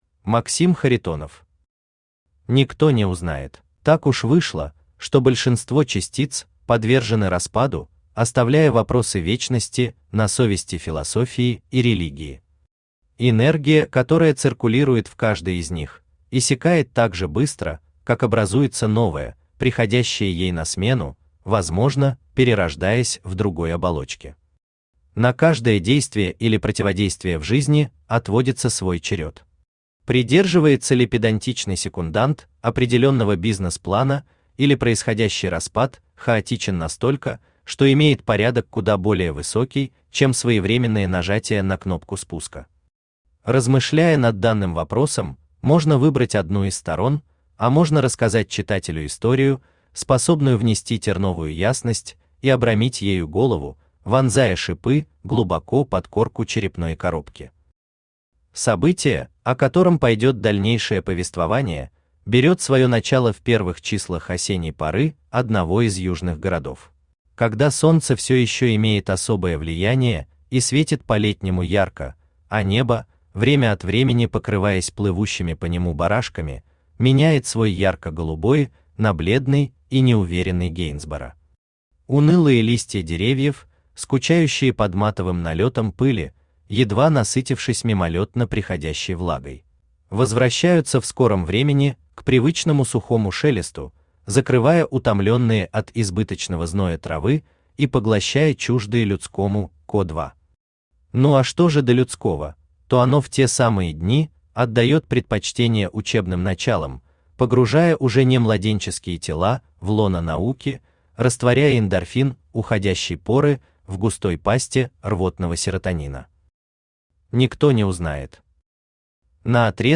Aудиокнига Никто не узнает Автор Максим Александрович Харитонов Читает аудиокнигу Авточтец ЛитРес.